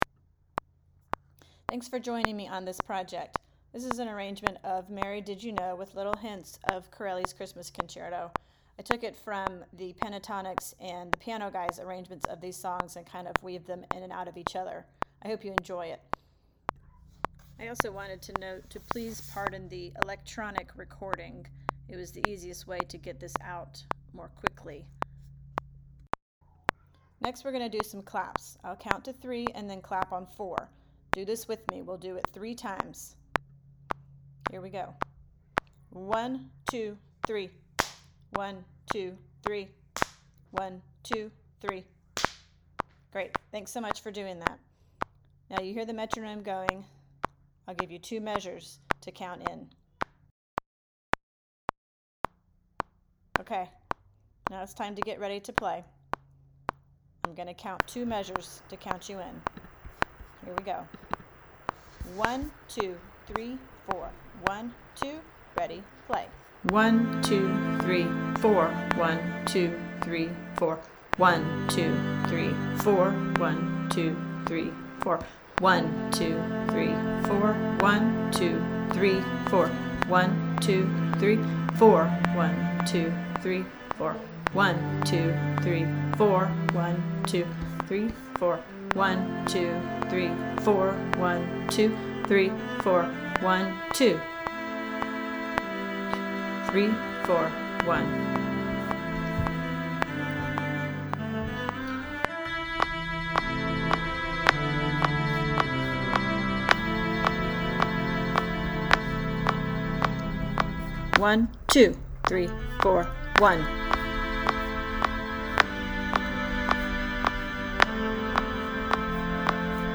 This is just cellos.